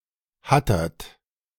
Hattert (German pronunciation: [ˈhatɐt]
De-Hattert.ogg.mp3